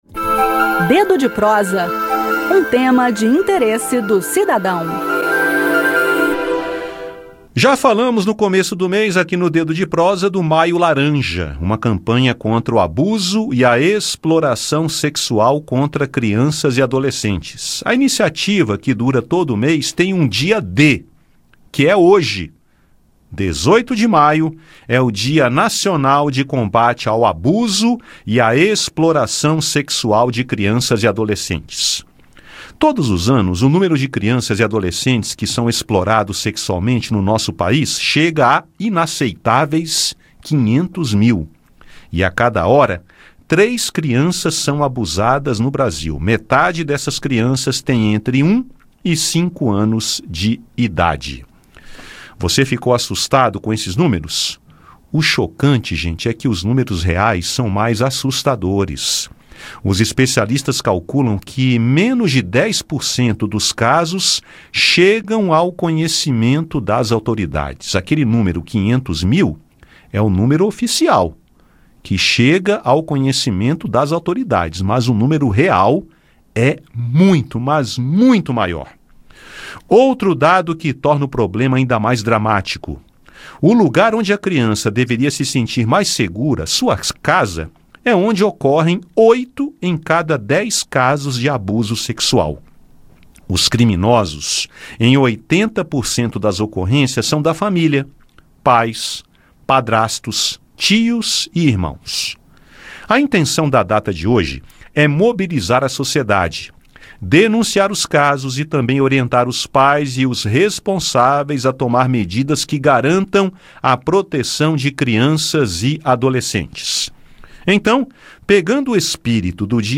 Conexão Senado Dedo de Prosa